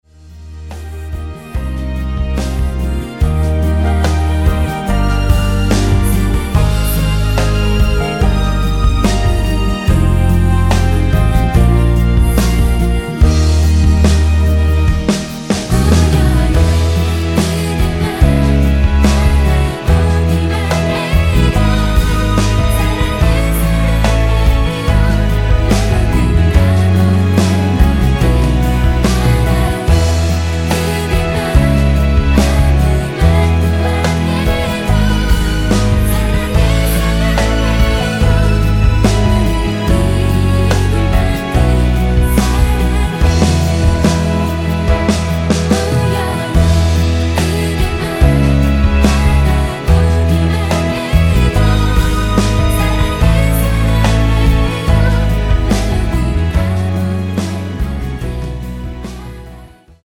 코러스 포함된 MR 입니다.
앞부분30초, 뒷부분30초씩 편집해서 올려 드리고 있습니다.
중간에 음이 끈어지고 다시 나오는 이유는